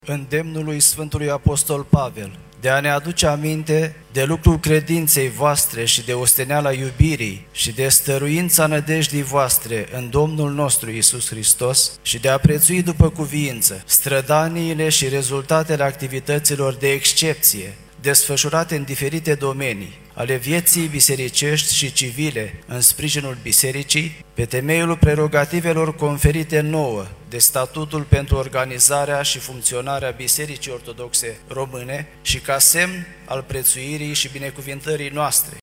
Episcopul vicar DAMASCHIN: